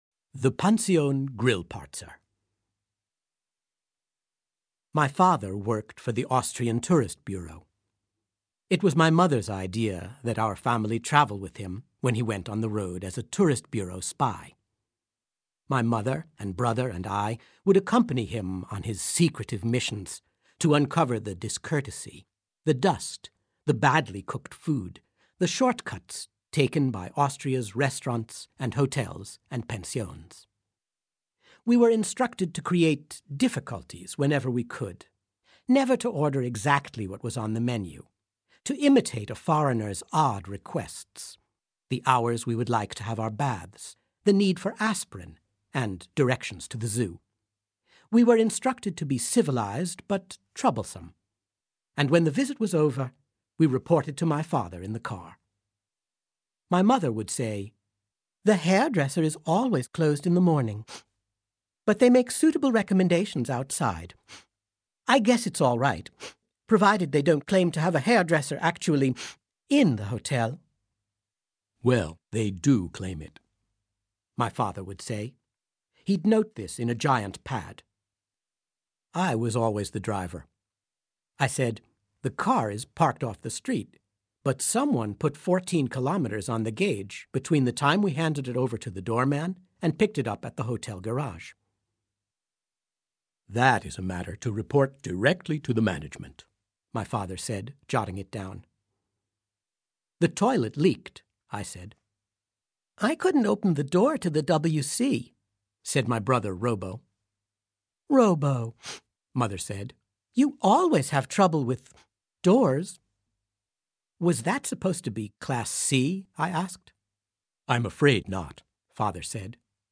Das Hörbuch zum Sprachen lernen.Ungekürzte Originalfassung / Audio-CD + Textbuch + CD-ROM